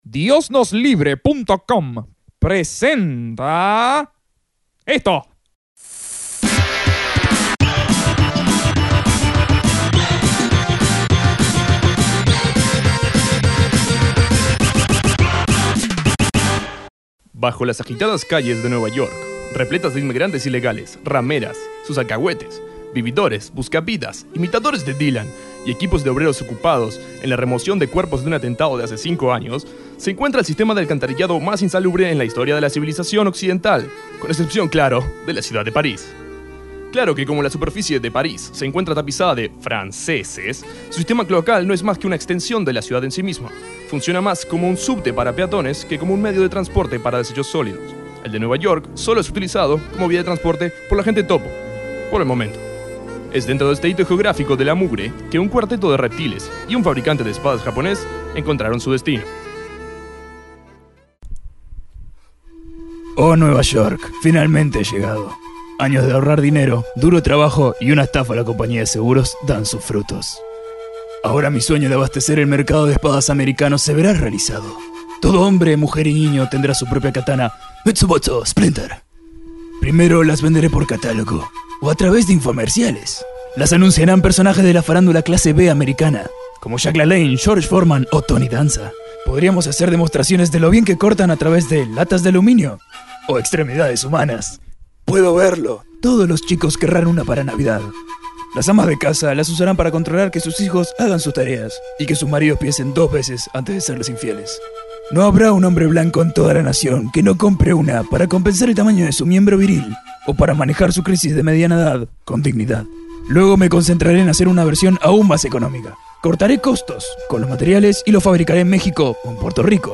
Radionovela de Las Tortugas Ninja